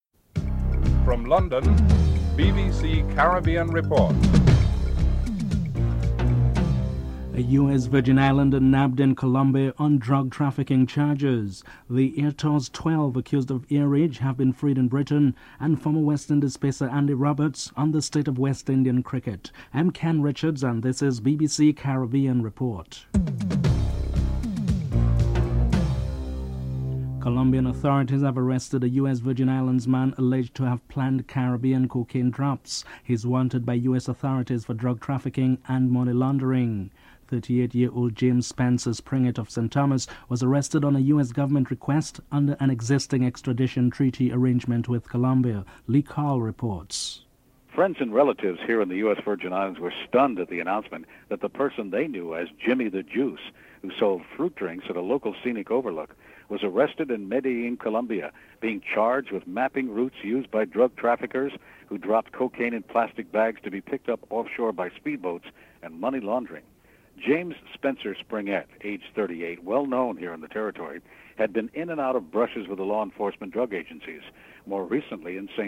1. Headlines